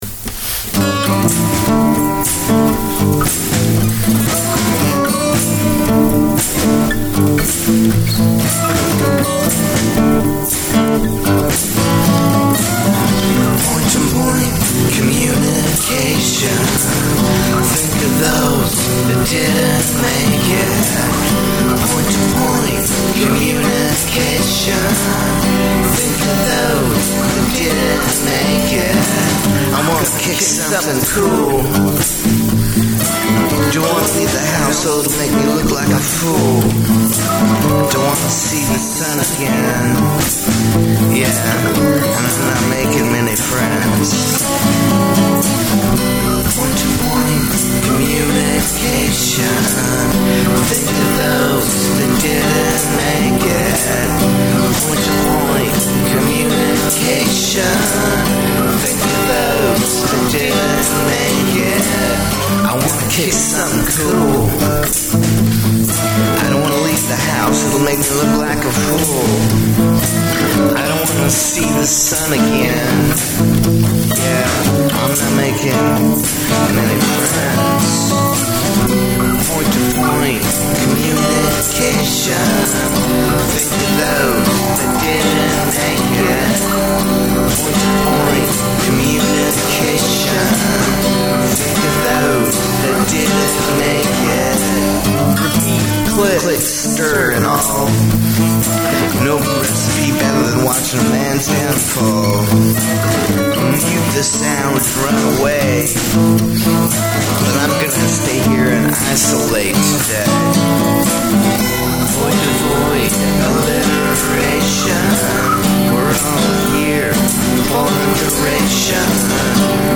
New tune that I wrote in the past few hours. Hopefully the vocals are loud enough in the mix.